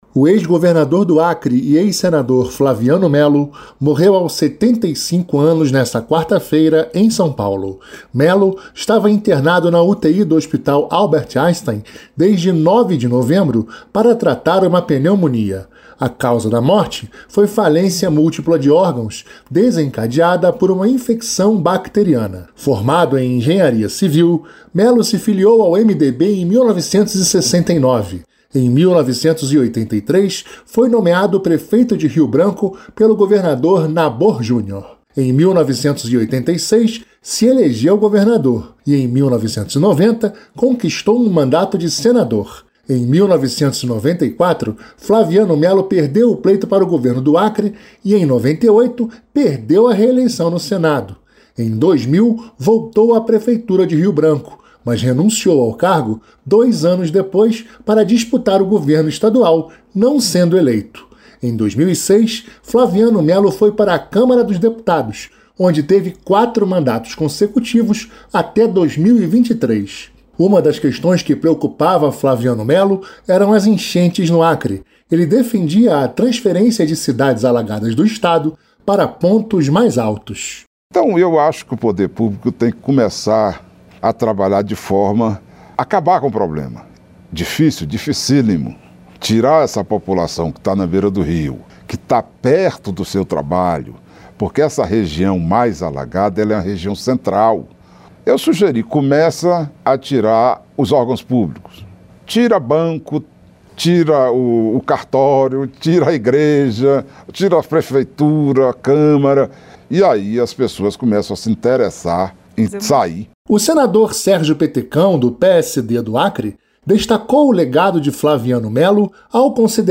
Nota de falecimento